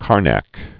(kärnăk, kär-näk)